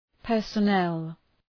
Προφορά
{,pɜ:rsə’nel}